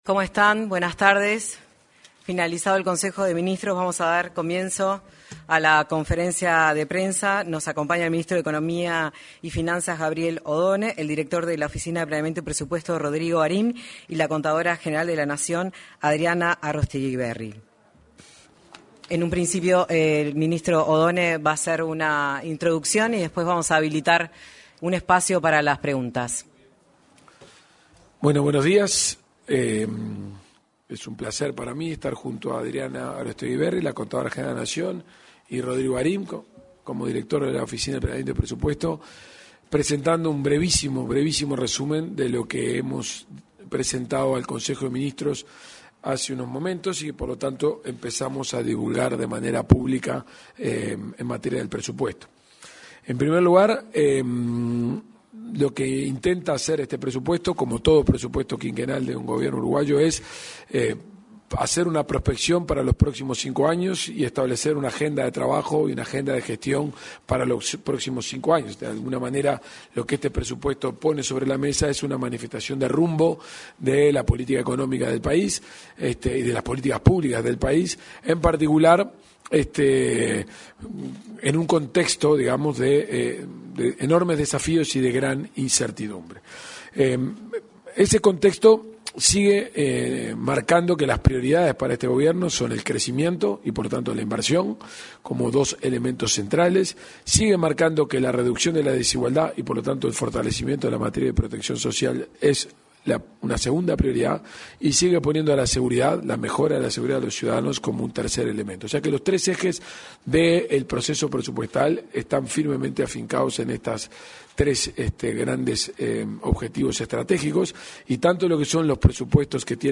Ministro de Economía, Gabriel Oddone, y contadora general de la Nación, Adriana Arosteguiberry, en conferencia de prensa
Ministro de Economía, Gabriel Oddone, y contadora general de la Nación, Adriana Arosteguiberry, en conferencia de prensa 28/08/2025 Compartir Facebook X Copiar enlace WhatsApp LinkedIn Luego de finalizada la reunión del Consejo de Ministros, se expresaron en una conferencia de prensa, en la Torre Ejecutiva, el ministro de Economía y Finanzas, Gabriel Oddone, y la contadora general de la Nación, Adriana Arosteguiberry.